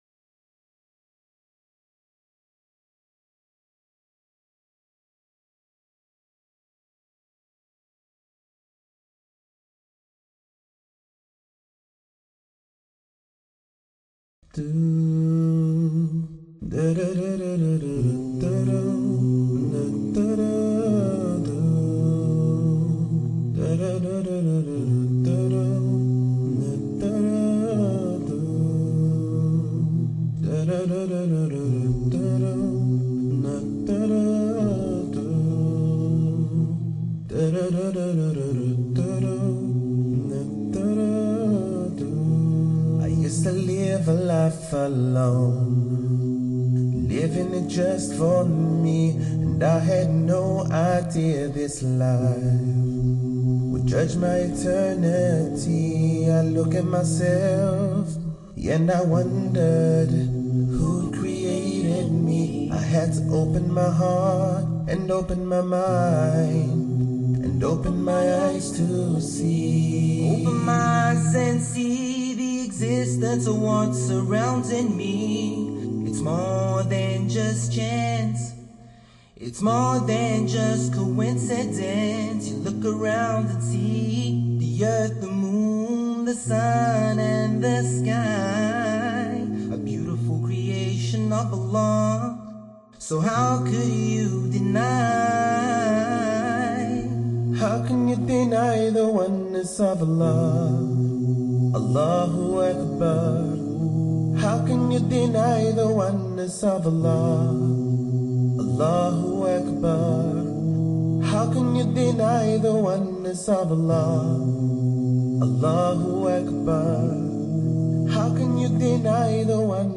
How Can You Deny? – Nasheed